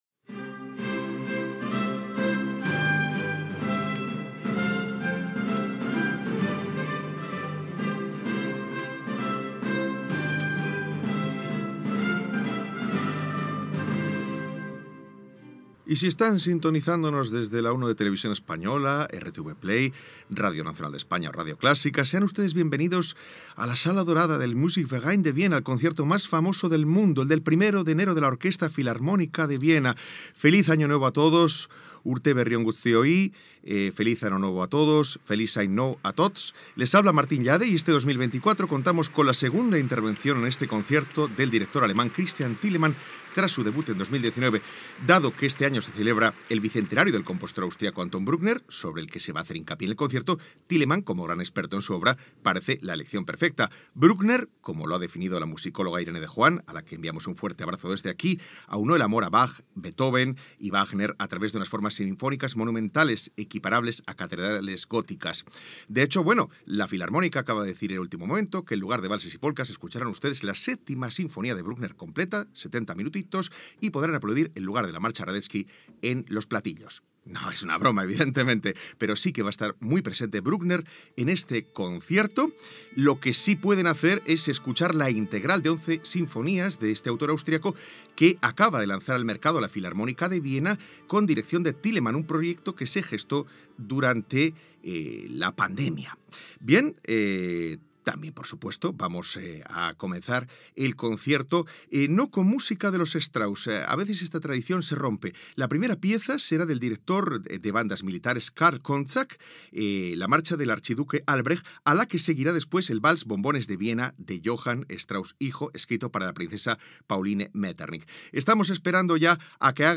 Sintonia d'Eurovisió, benvinguda a la transmisssió del concert d'any nou de la Filharmònica de Viena, dades sobre el compositor Anton Bruckner i presentació del director Christian Thielemann i del primer tema
Musical